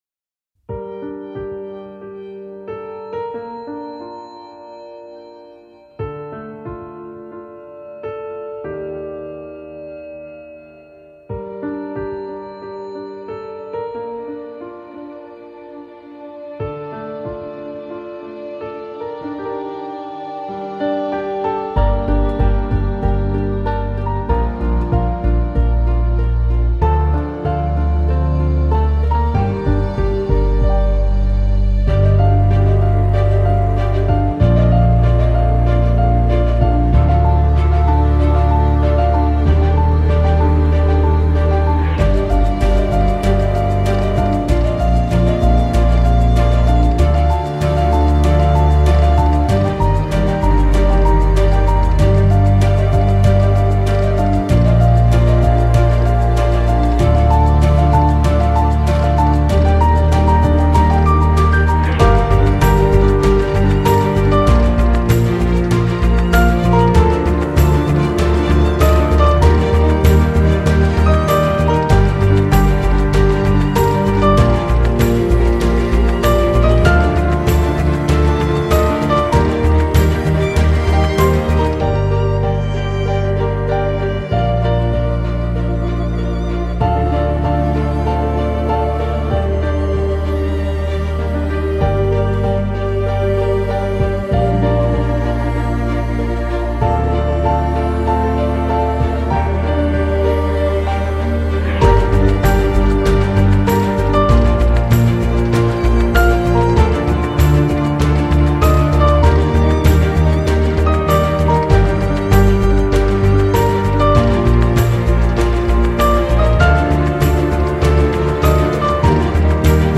aerien - nostalgique - calme - melancolie - piano